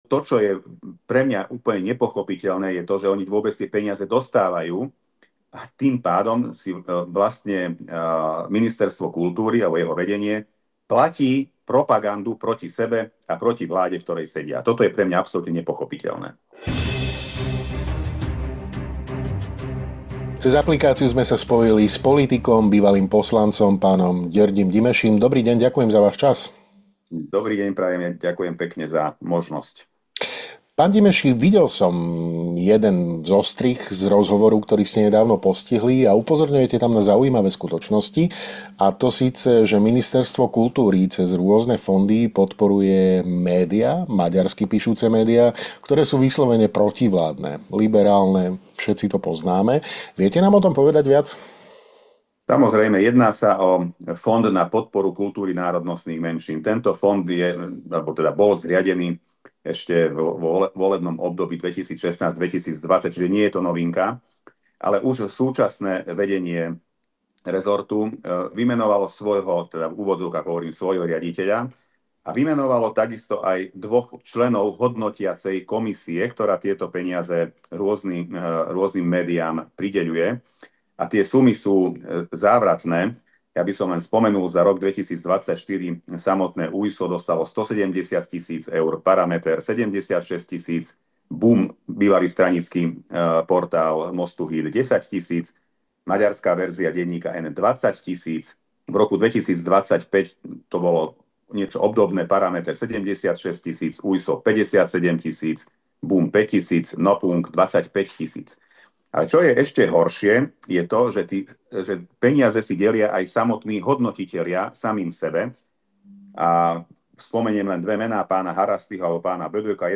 Dozviete sa vo videorozhovore s politikom a bývalým poslancom NR SR, PhDr. Györgym Gyimesim, PhD. .